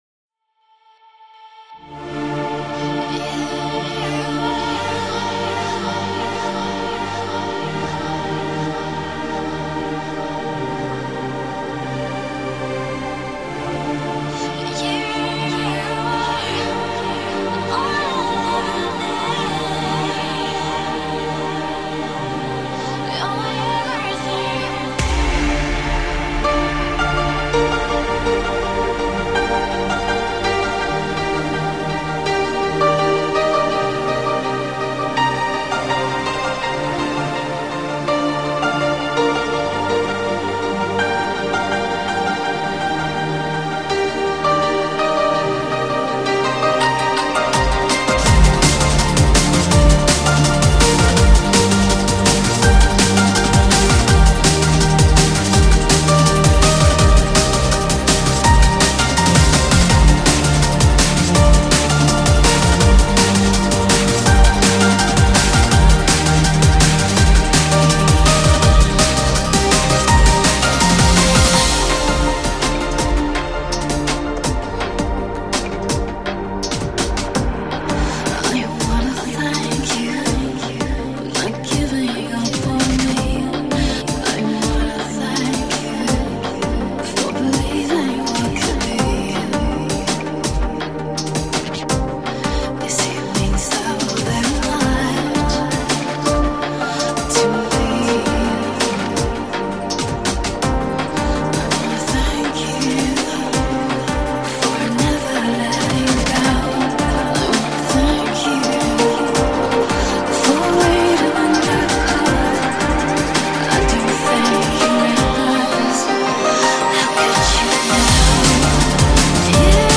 Файл в обменнике2 Myзыкa->DJ's, транс
Подстиль: Vocal Trance / Drum
Позитив | Энергия | Чувство | Ритм | Стиль | Движение